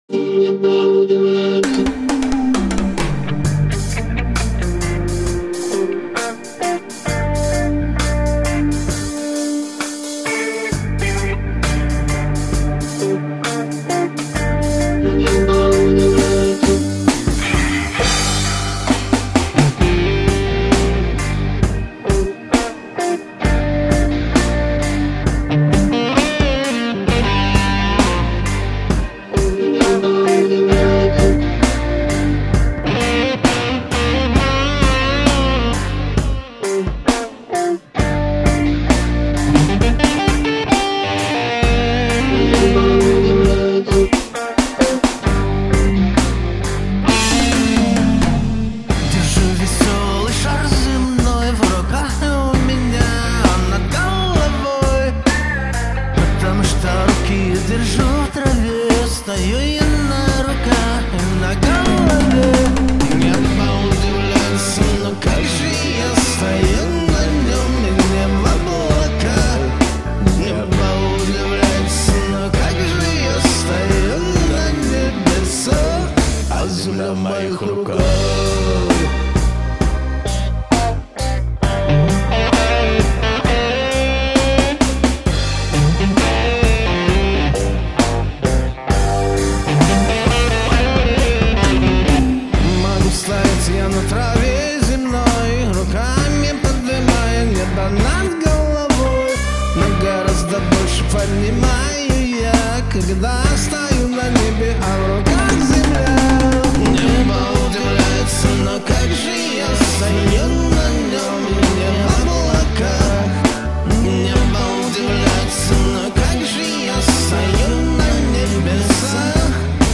• Жанр: Арт-рок